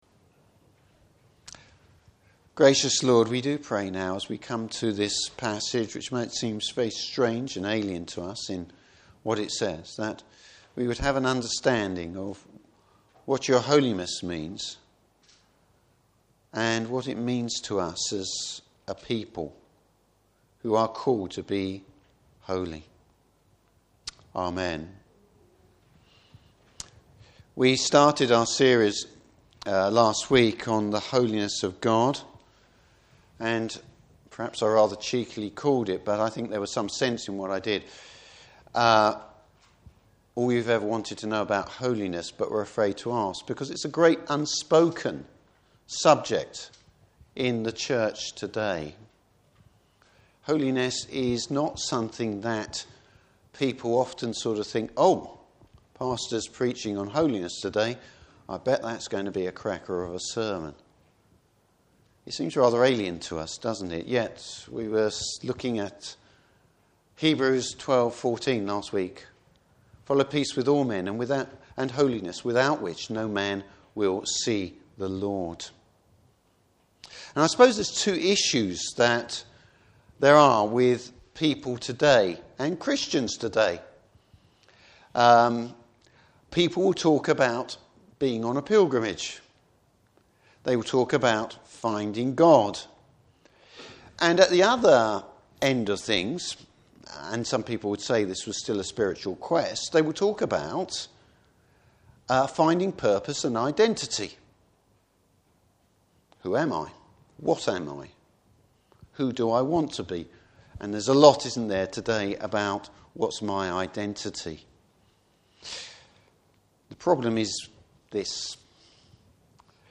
Service Type: Morning Service Why God’s holiness must be taken seriously.